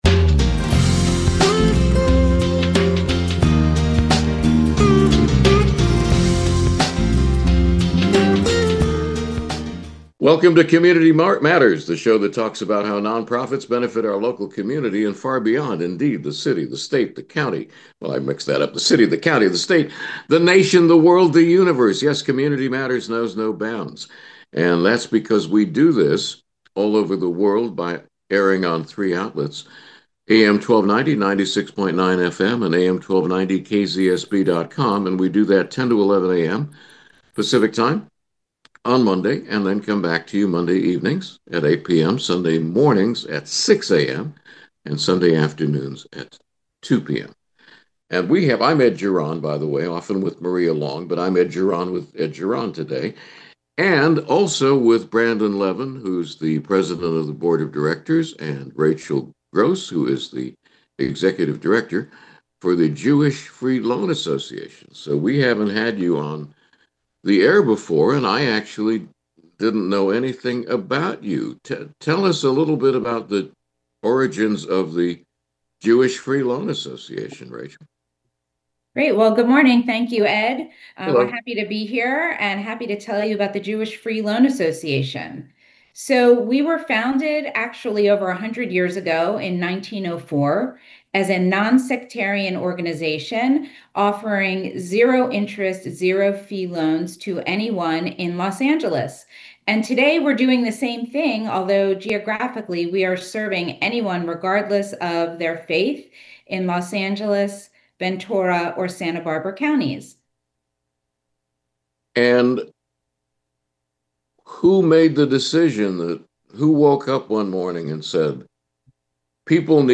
KZSB AM 1290 — March 2026 interview